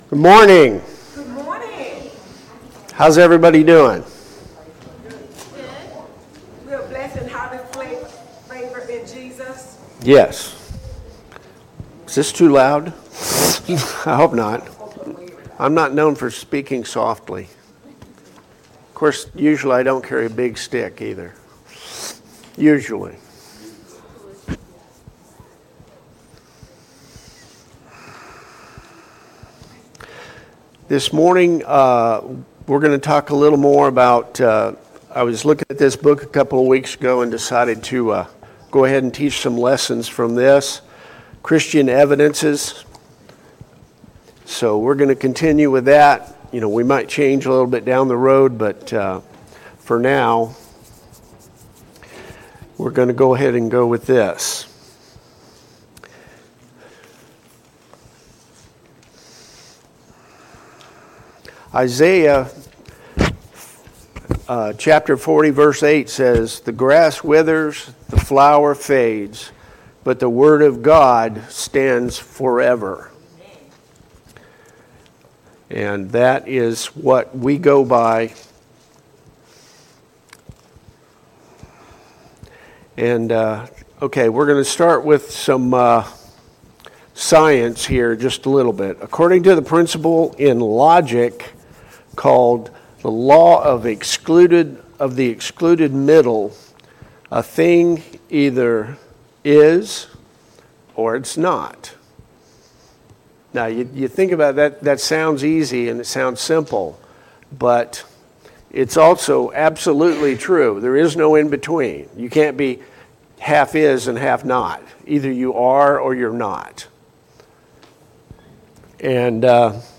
Service Type: Sunday Morning Bible Class Topics: Apologetics , Christian Evidences